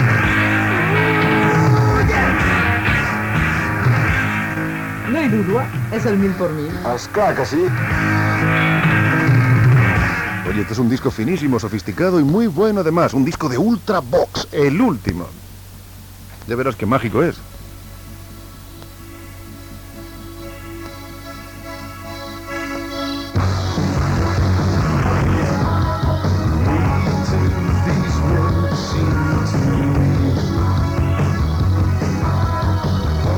Identificació del programa i presentació d'un tema musical
FM